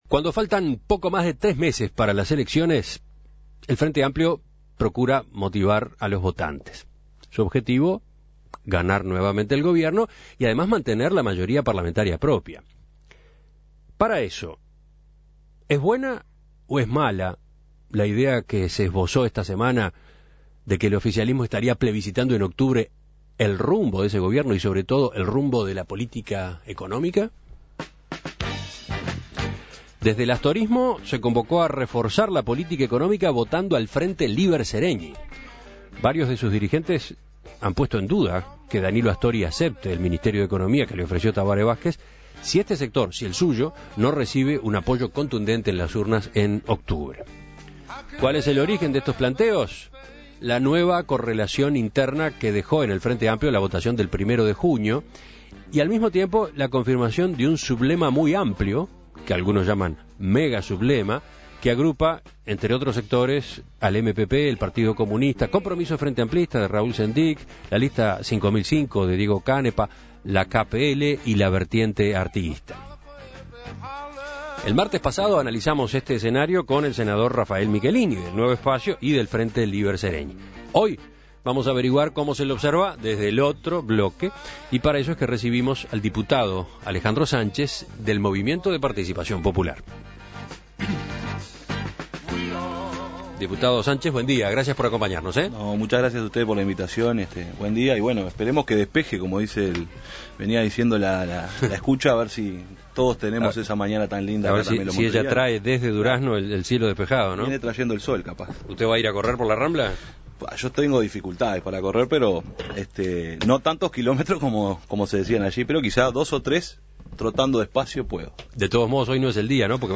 En esta oportunidad, En Perspectiva entrevistó al diputado por el MPP Alejando Sánchez.